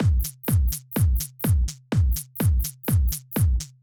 Drumloop 125bpm 10-C.wav